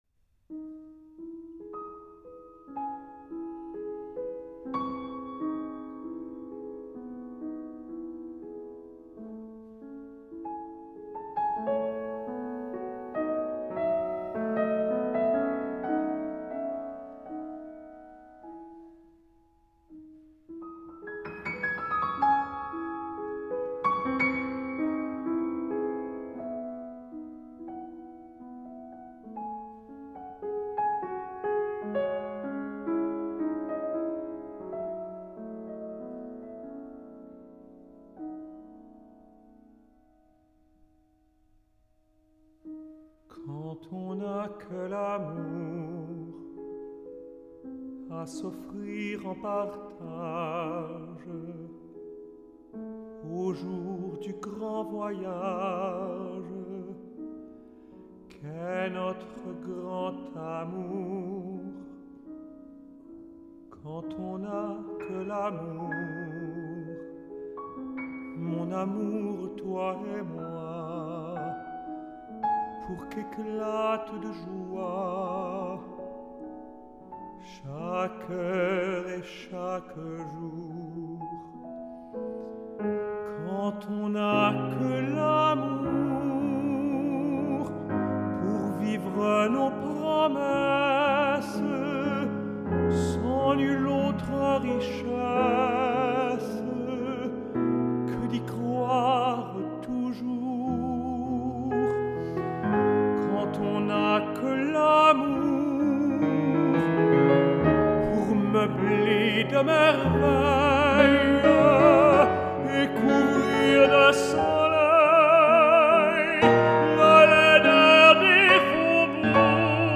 for Tenor and Piano